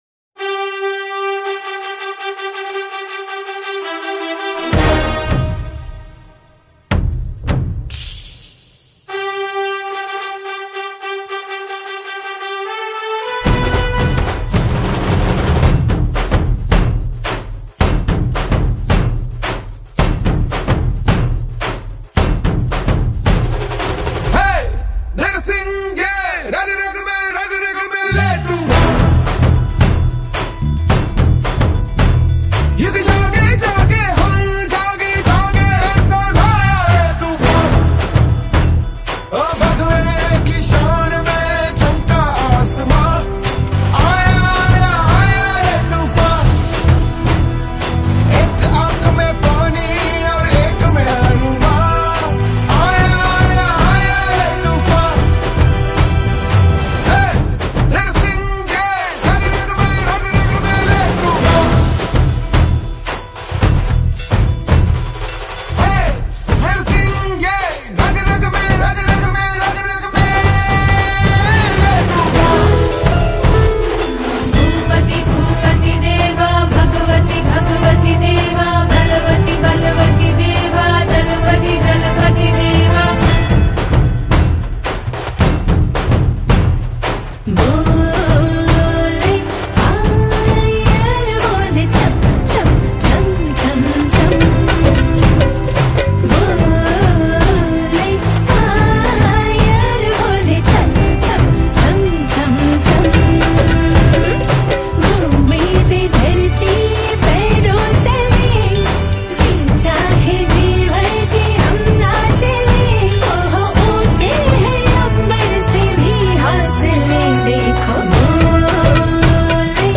The lyrics, music, and vocals all carry a powerful impact.
Bollywood Songs